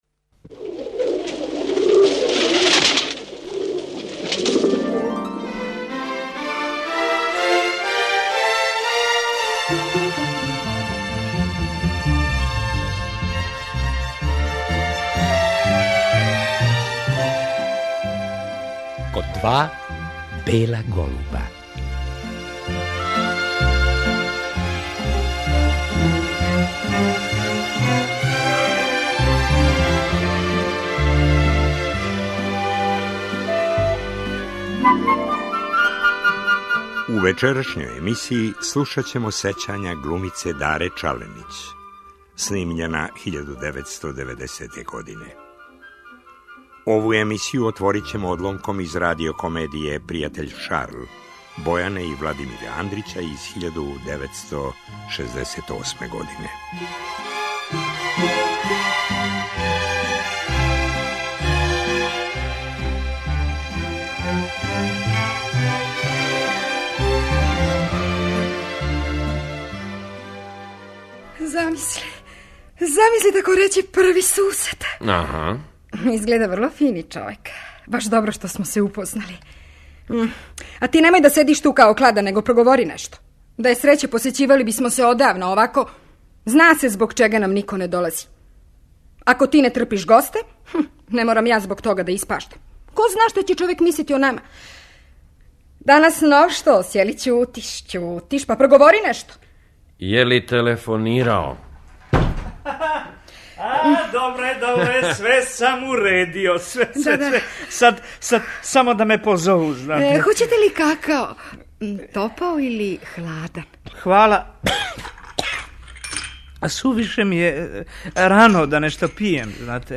Гост емисије ''Код два бела голуба'' била је октобра 1990. године.